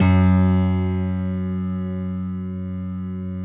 Index of /90_sSampleCDs/Optical Media International - Sonic Images Library/SI1_Six Pianos/SI1_Distantpiano